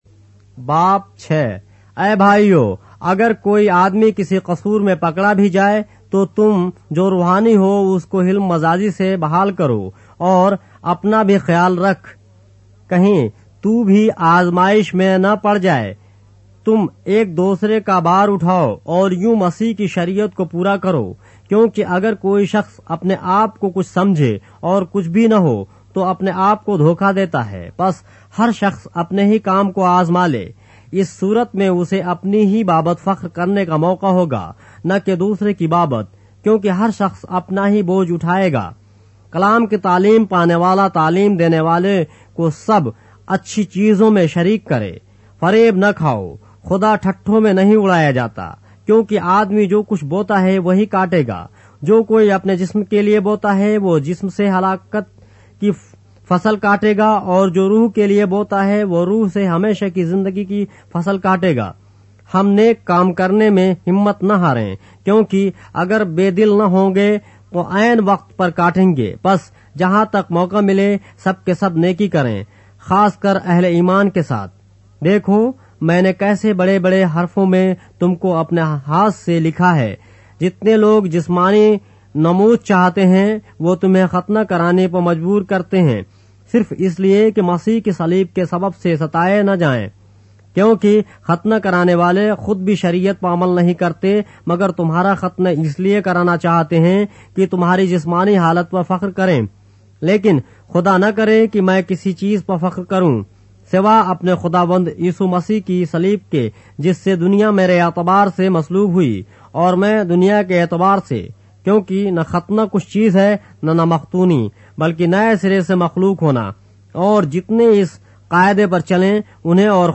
اردو بائبل کے باب - آڈیو روایت کے ساتھ - Galatians, chapter 6 of the Holy Bible in Urdu